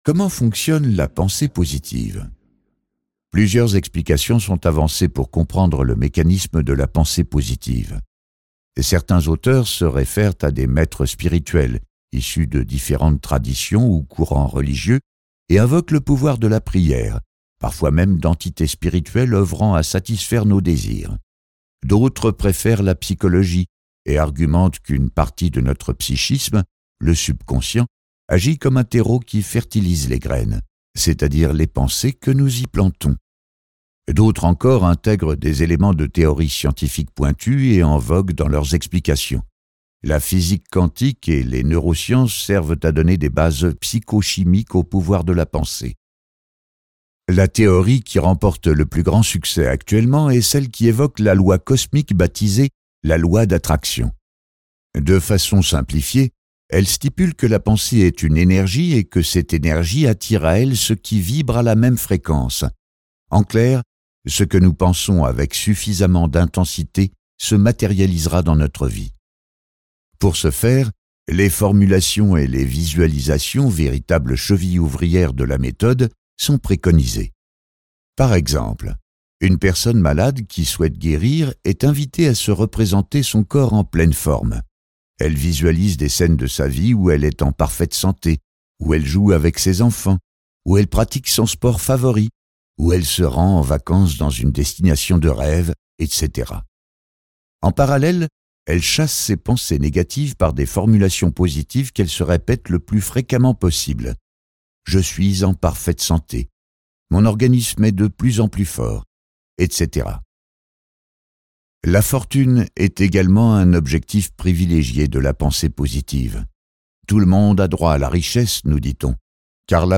À découvrir aussi de toute urgence : Au diable la culpabilité ! et Petit cahier d'exercices pour vivre libre et cesser de culpabiliser (Éditions Jouvence).Ce livre audio est interprété par une voix humaine, dans le respect des engagements d'Hardigan.